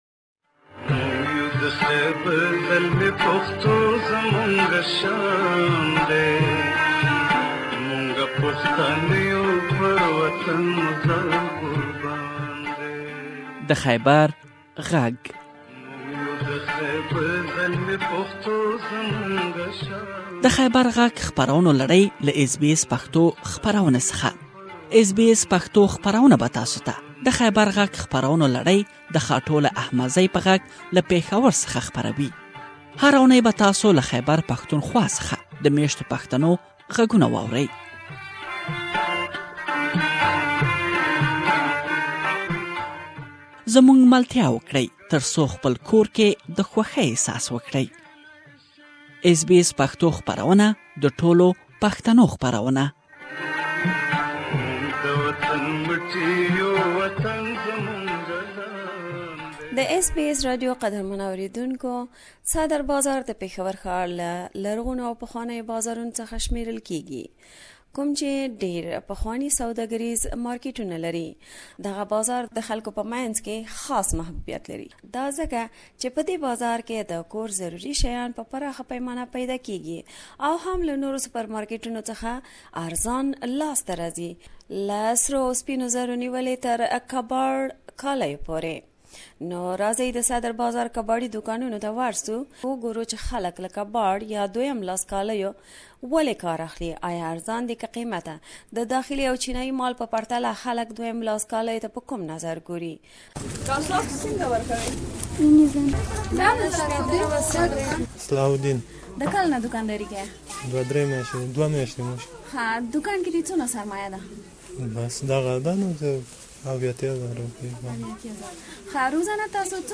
Please listen to her full report here.